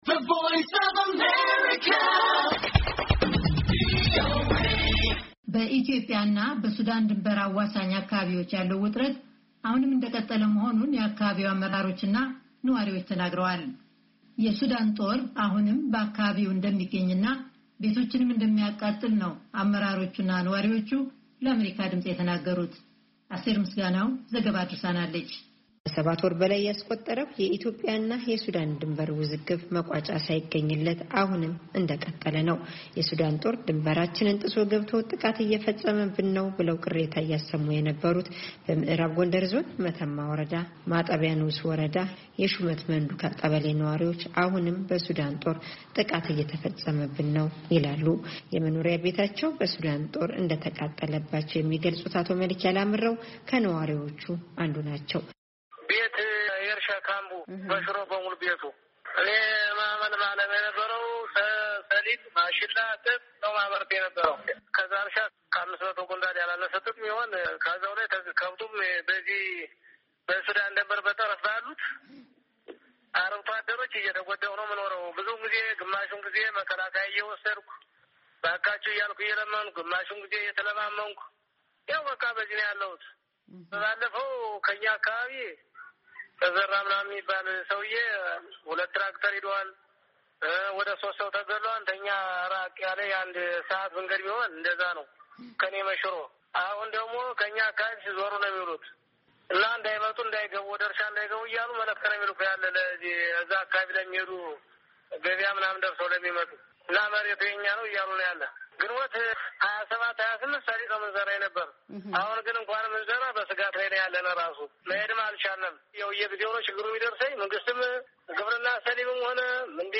በኢትዮጵያ እና በሱዳን ድንበር አዋሳኝ አካባቢዎች ያለው ውጥረት፣ አሁንም እንደቀጠለ መሆኑን የአካባቢው አመራሮችና ነዋሪዎች ተናግረዋል። የሱዳን ጦር አሁንም በአካባቢው እንደሚገኝ እና ቤቶችንም እንደሚያቃጥል፤ አመራሮቹና ነዋርዎቹ ለአሜሪካ ድምፅ ተናግረዋል። ዝርዝሩን ከተያያዘው የድምጽ ፋይል ያድምጡ፡፡ የኢትዮጵያ እና ሱዳን ድንበር አዋሳኝ አካባቢዎች ሁኔታ